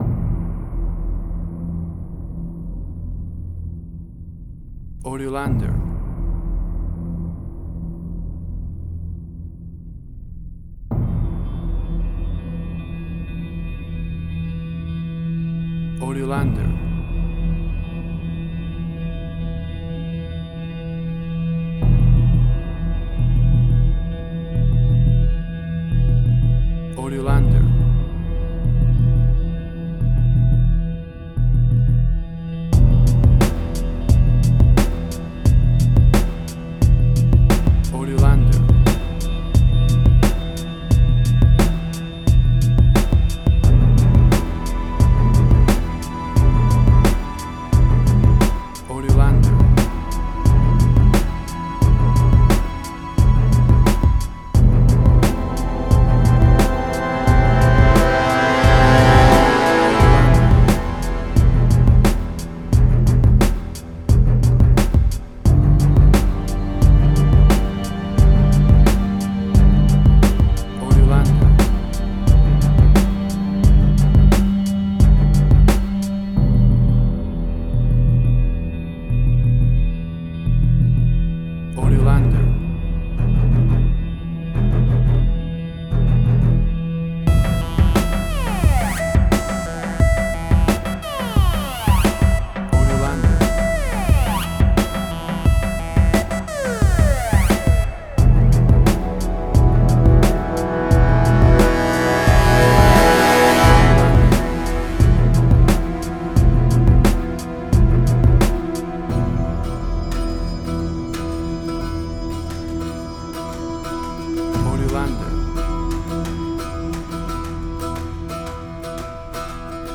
Suspense, Drama, Quirky, Emotional.
WAV Sample Rate: 16-Bit stereo, 44.1 kHz
Tempo (BPM): 88